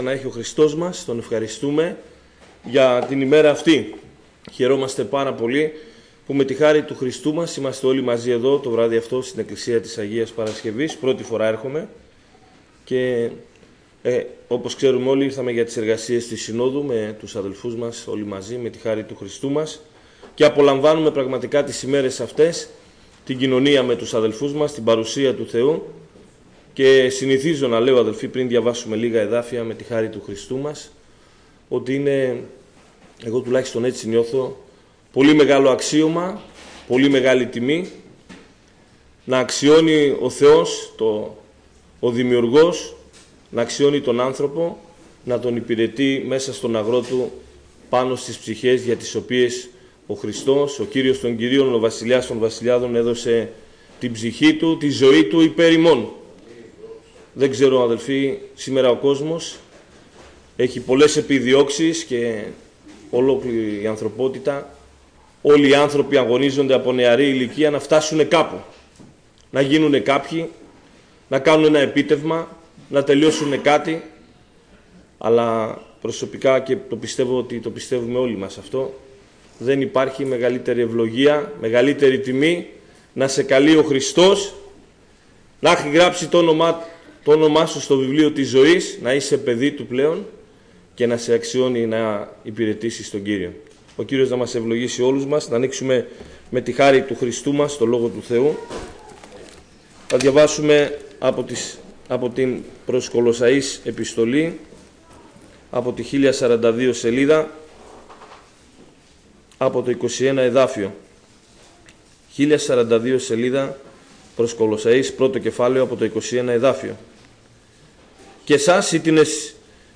Μηνύματα Διαφόρων Αδερφών με αφορμή την σύνοδο εργατών στην Αθήνα, στις 20/03/2026